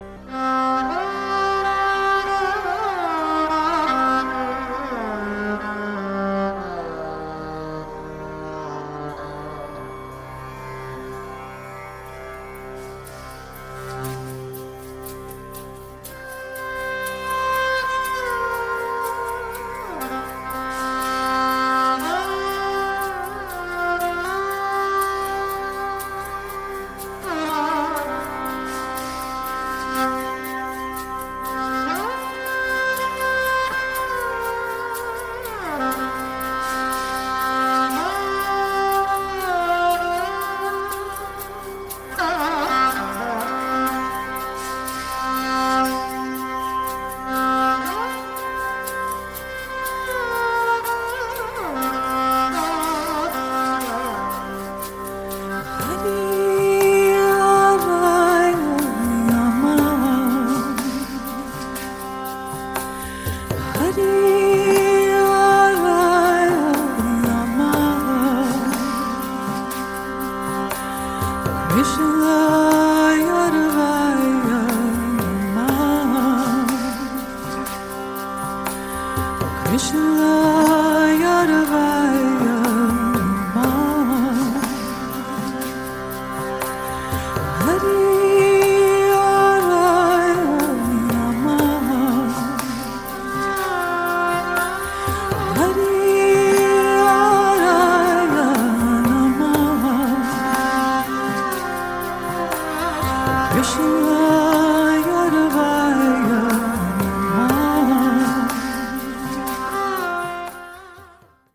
印度音乐演唱会